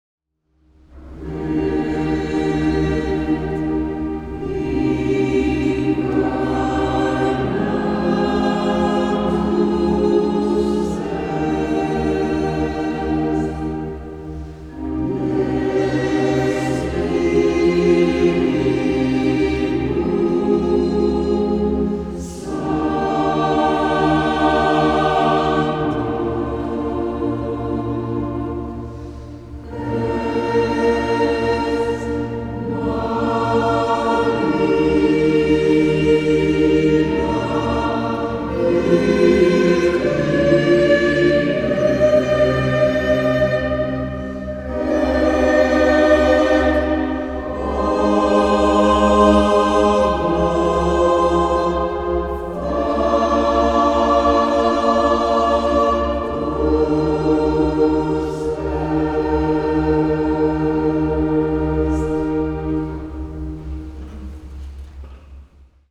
SCHOLA CANTORUM Sedico (Belluno)
Sedico 25_03_2025